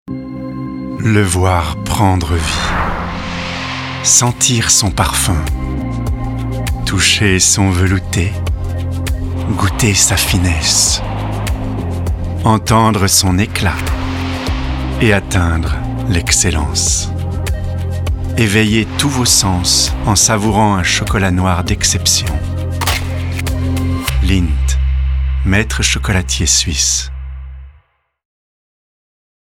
Voix off
Lindt Excellence (Publicité)
- Baryton-basse